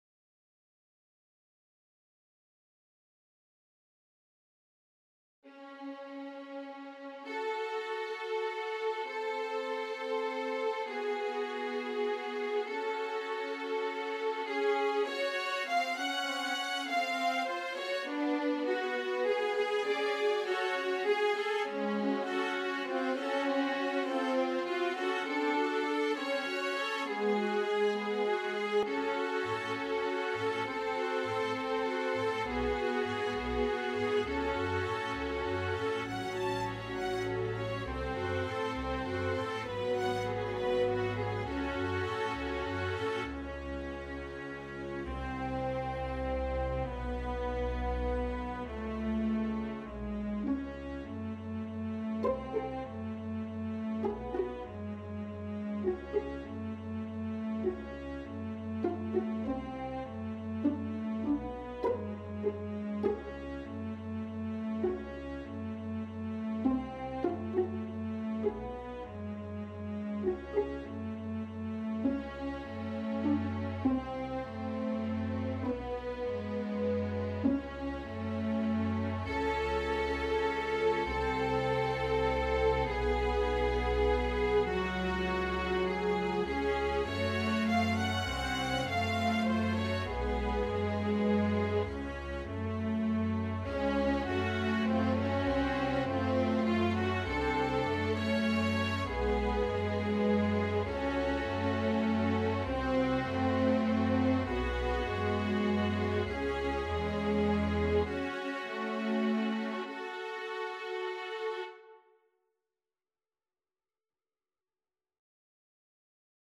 Help with String Quintet